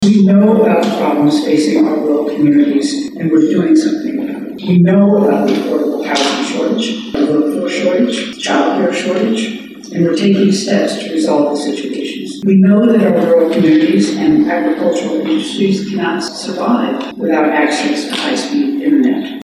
The Kansas Farm Bureau hosted its annual meeting Sunday, with Gov. Laura Kelly as the key guest speaker.